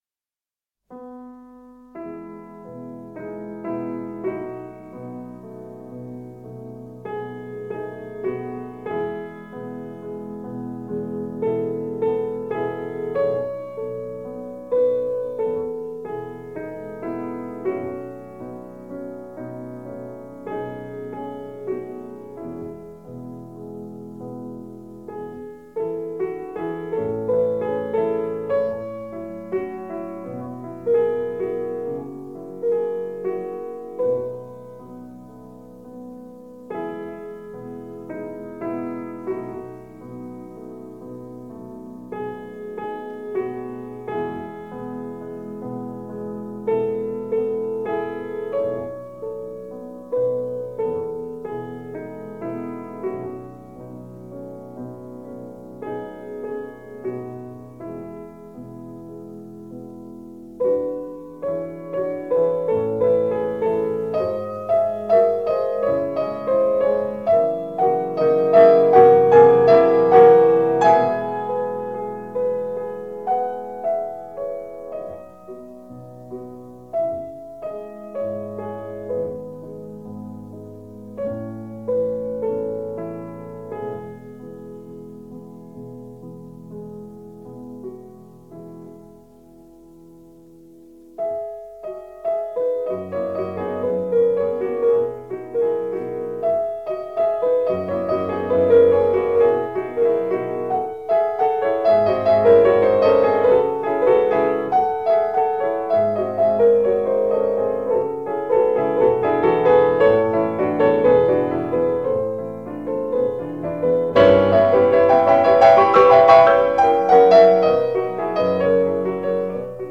Святослав Рихтер - Шопен. Этюд ми мажор, соч.10 №3 (1952)